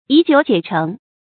以酒解酲 yǐ jiǔ jiě chéng
以酒解酲发音